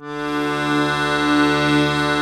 D1 BUTTON -R.wav